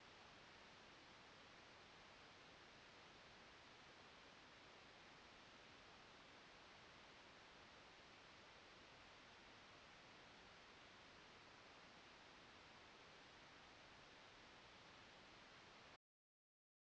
Всем добрый день,возникла такая проблема с Октавой мкл 5000(микрофон)...сначала при записи редко появлялся треск....думал что это шнур..проверил шнуры нет не они,после подумал на БП,но нет тоже исправен,решил легонько постучать по зоне где регулируется направленность микрофона,и треск становился постоянным и сильны...отвез "мастеру"...треска больше нет...я счастлив,но..теперь есть шум...пи котором работать просто не возможно...."мастер"не знает почему он может быть...пришлось заказать лампу СИМЕНС...только такой шум это проблема в лампе??